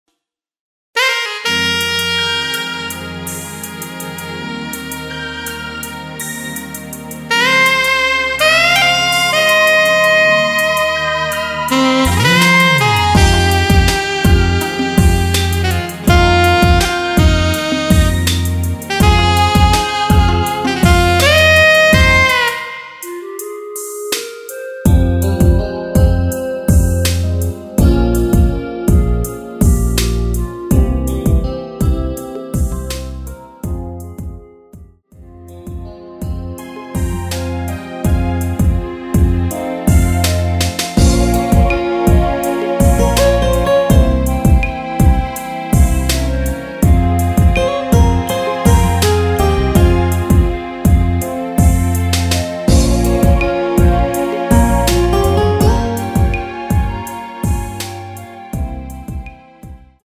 원키에서(+1)올린 멜로디 포함된 MR입니다.
Fm
음질도 좋고 다른 곳 보다 훨씬 좋습니다 감사합니다 .
앞부분30초, 뒷부분30초씩 편집해서 올려 드리고 있습니다.
(멜로디 MR)은 가이드 멜로디가 포함된 MR 입니다.